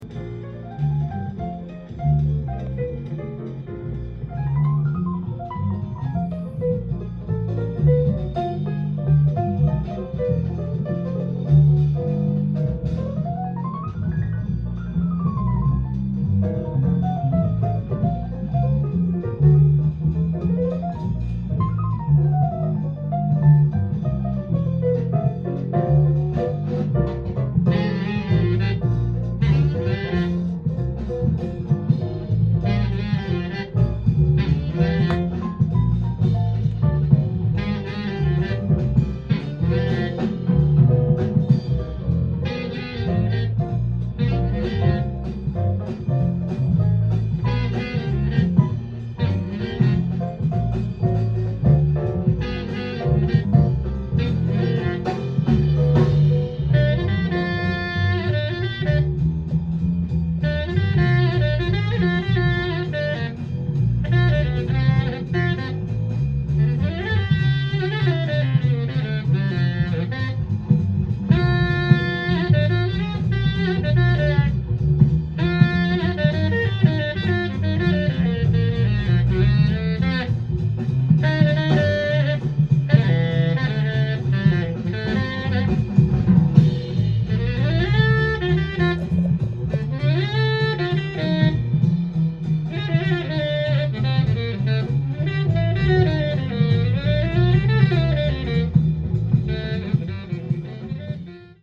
店頭で録音した音源の為、多少の外部音や音質の悪さはございますが、サンプルとしてご視聴ください。
1973年、デンマーク、コペンハーゲンでのライブ録音盤。